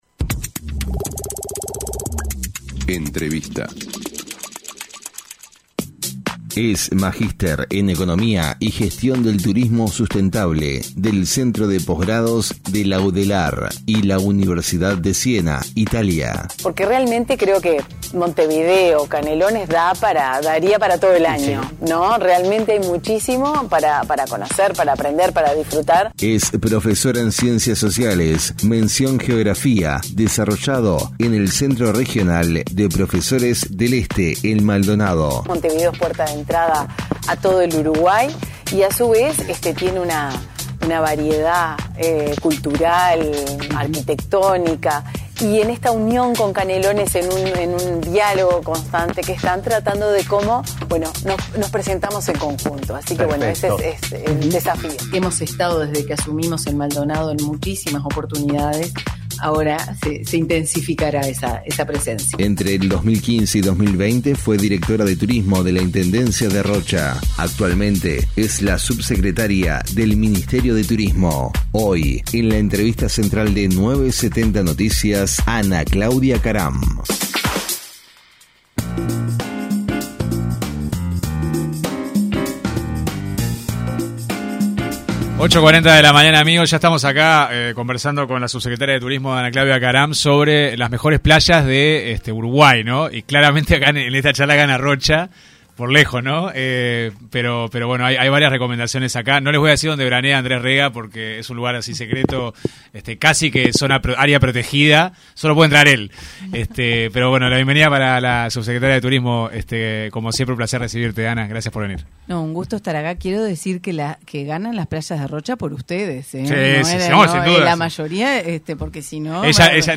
La subsecretaria del Ministerio de Turismo, Ana Claudia Caram se refirió en diálogo con 970 Noticias, al aumento de precios en los supermercados con motivo de la temporada alta y llegada de los turistas extranjeros.